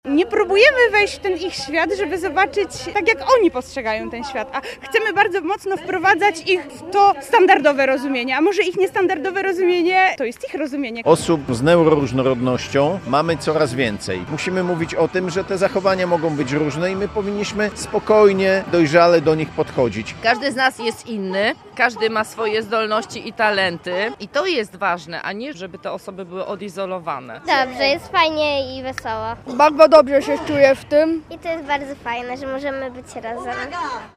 Janusza Korczaka w Koninie, zastępca prezydenta Konina Witold Nowak oraz inni uczestnicy.